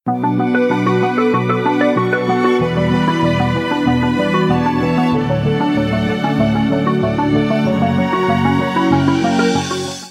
• Качество: 256, Stereo
красивые
без слов
инструментальные